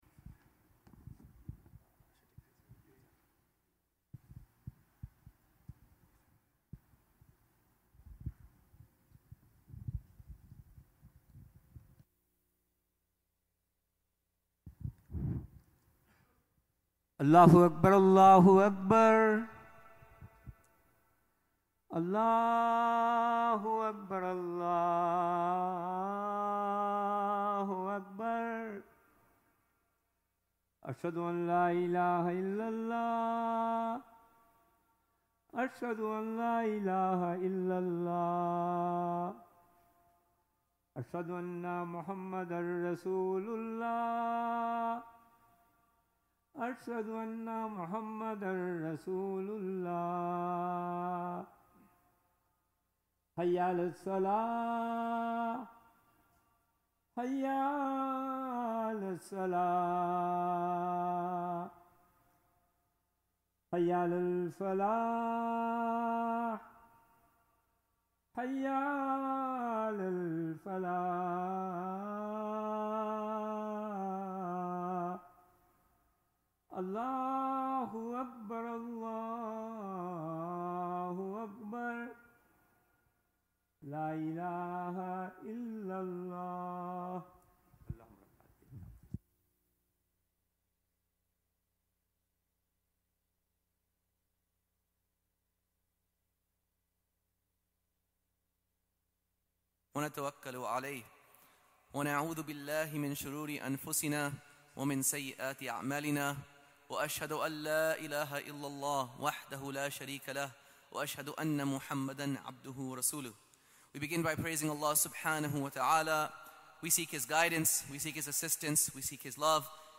Friday Khutbah - "Verifying Information"